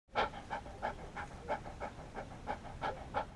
دانلود صدای سگ 5 از ساعد نیوز با لینک مستقیم و کیفیت بالا
جلوه های صوتی